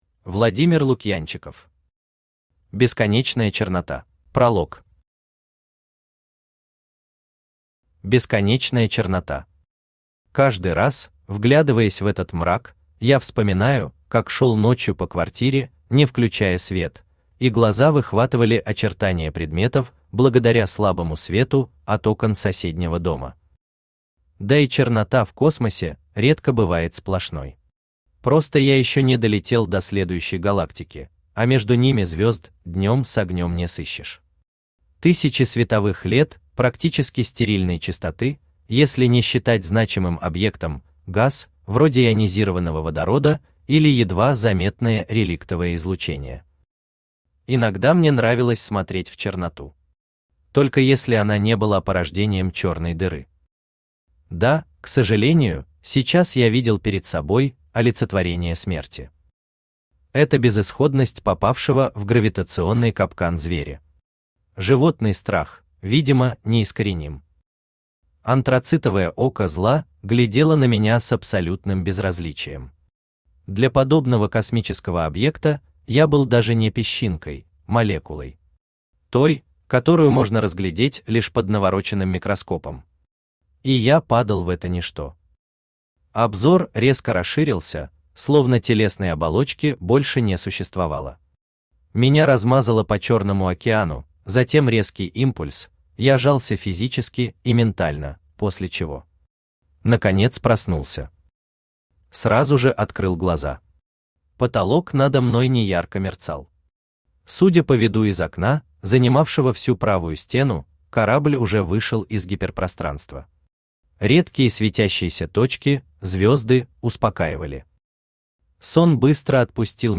Читает: Авточтец ЛитРес
Аудиокнига «Бесконечная чернота».